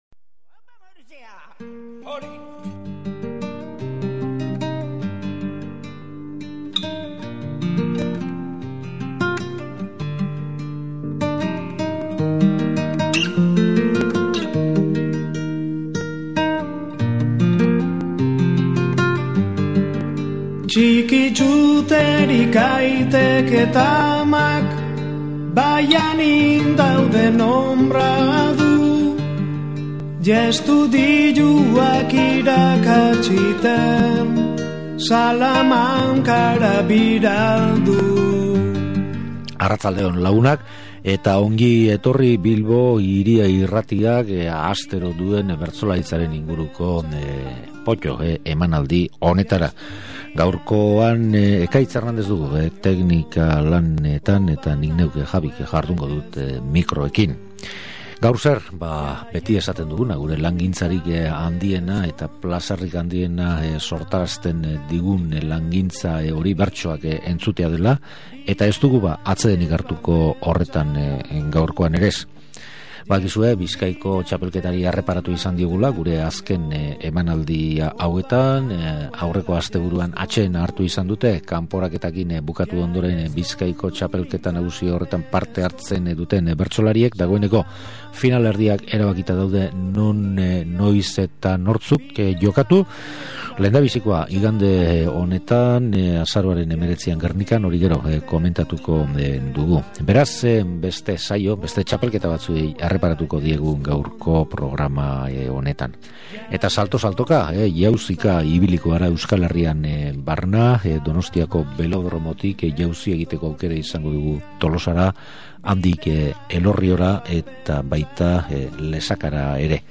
Bizkaiko txapelketa atseden harturik egon izan denez Euskal Herrian barna azken urteetan egindako txapelketa ezberdinetatik harturiko zenbait saio plazaratu ditugu oraingoan, hala nola 2001eko Belodromoko finala